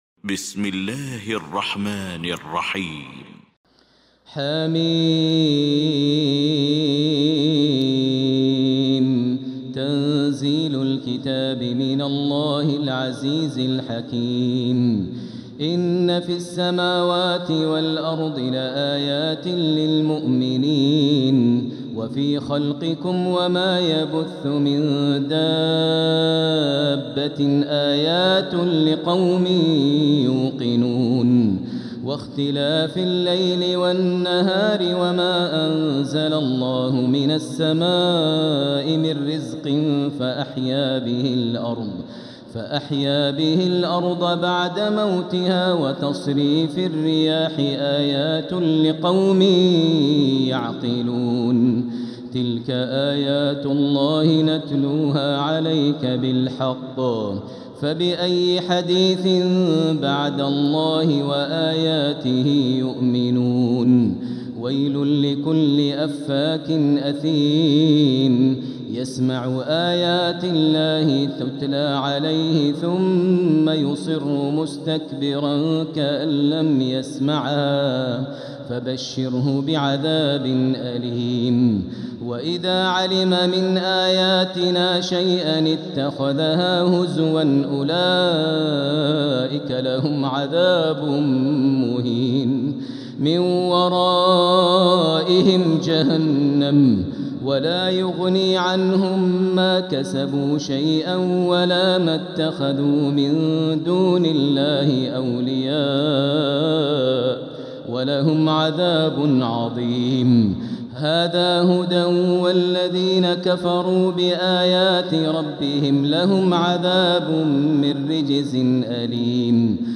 المكان: المسجد الحرام الشيخ: فضيلة الشيخ ماهر المعيقلي فضيلة الشيخ ماهر المعيقلي الجاثية